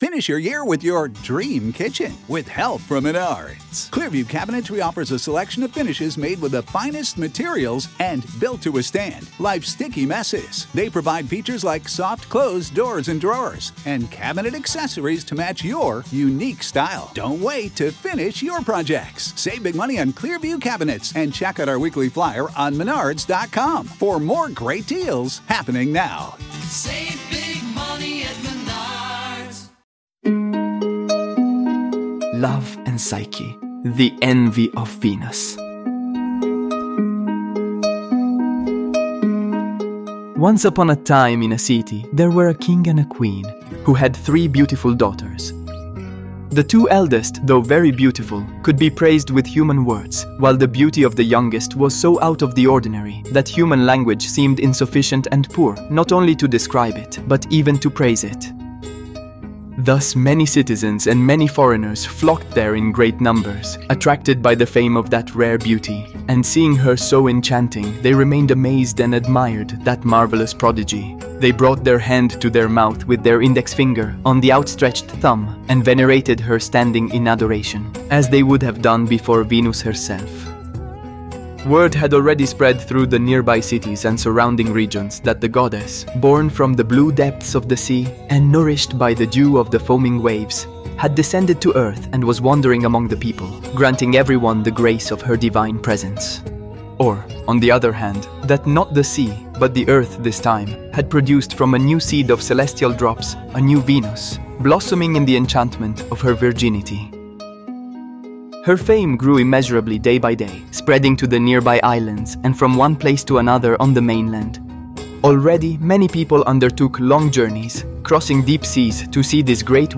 Our talented actors would like to give you a small gift: the tale of Cupid and Psyche from Apuleius's "Metamorphoses", presented in episodes.